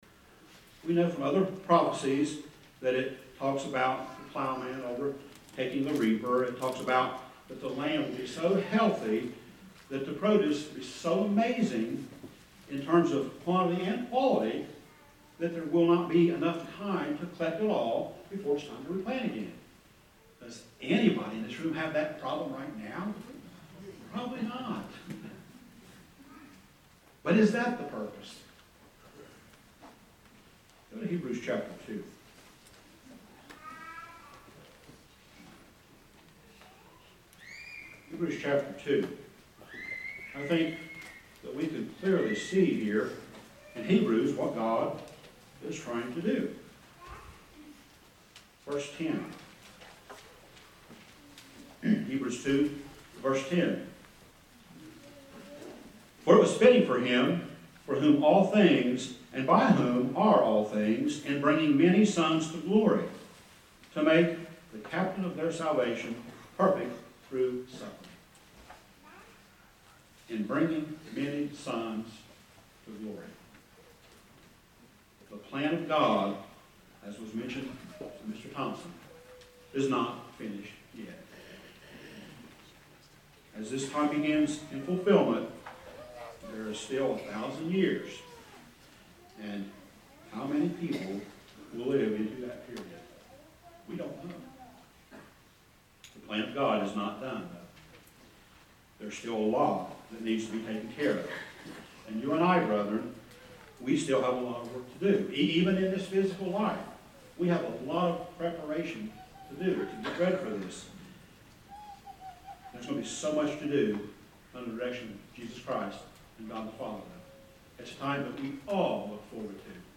This sermon was given at the Bastrop, Texas 2022 Feast site.